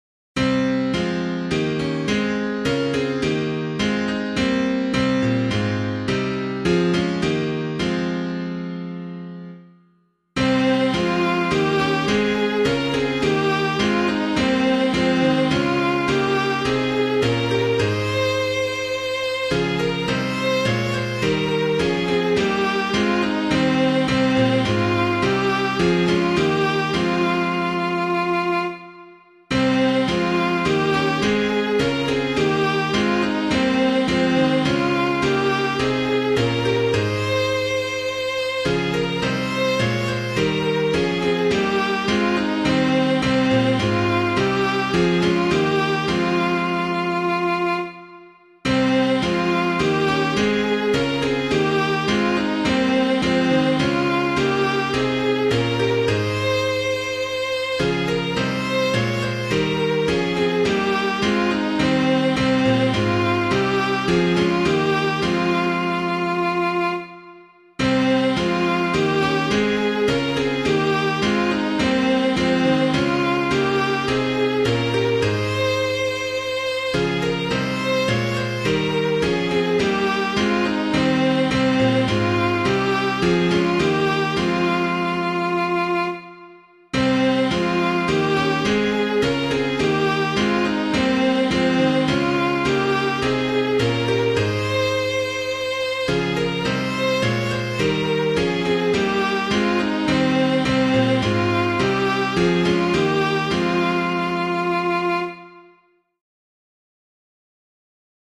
piano
A Morning Star Draws Near the Earth [Bringle - MORNING SONG] - piano.mp3